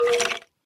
skeleton